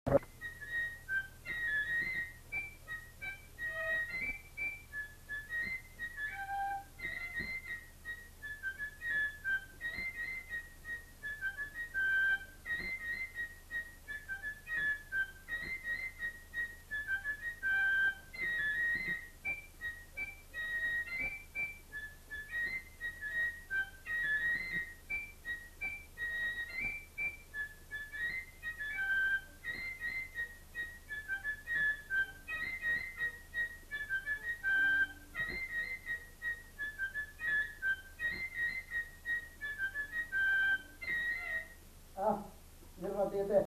Répertoire d'airs à danser joué à la flûte à trois trous et à l'harmonica
Scottish